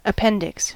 Ääntäminen
Synonyymit vermiform appendix Ääntäminen US UK : IPA : /əˈpɛn.dɪks/ US : IPA : /əˈpɛn.dɪks/ Lyhenteet ja supistumat (laki) app.